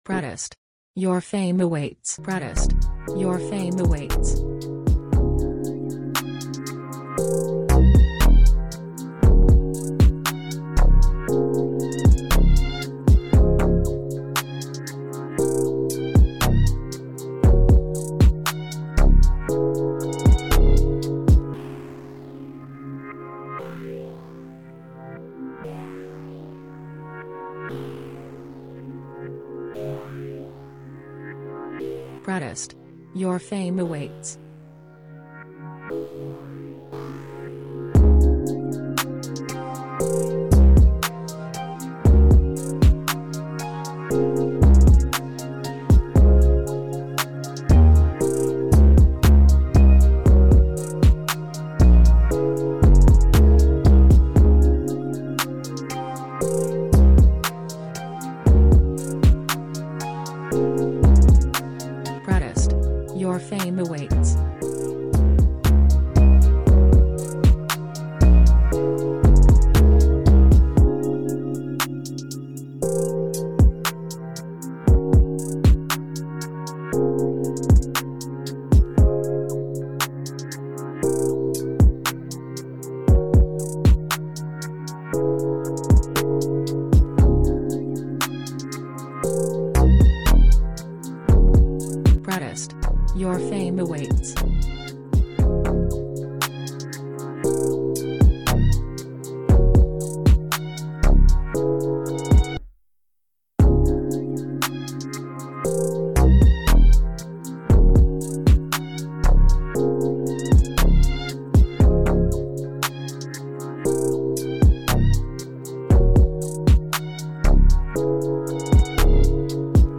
Rap, Pop, World